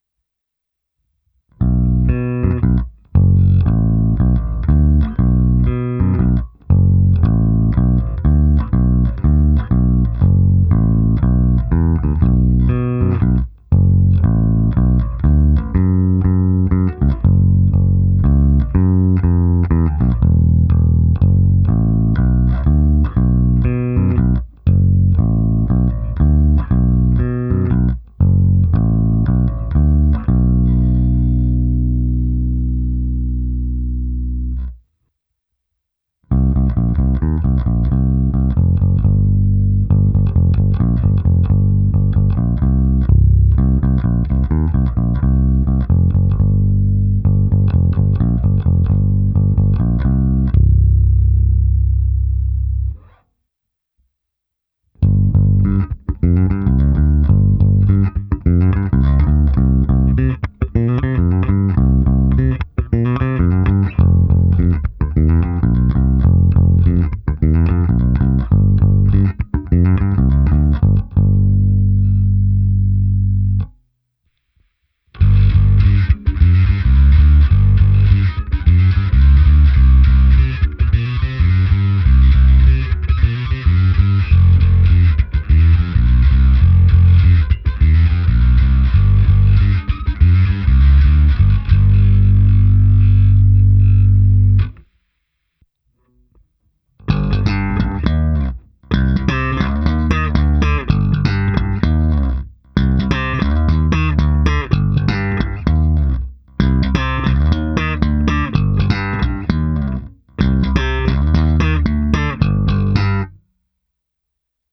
Perfektní je rovněž přednes struny H. Mám pocit, že tahle baskytara si říká vysloveně o nějaký vysloveně čistý zesilovač typu Markbass, Eich (TecAmp) a podobně, který jí nepokřiví charakter, nebo jen nějaký ten DI box a šup s tím rovnou do mixu.
Není-li uvedeno jinak, následující nahrávky jsou provedeny rovnou do zvukové karty, s plně otevřenou tónovou clonou a na korekcích jsem trochu přidal jak basy, tak výšky.
Ukázka struny H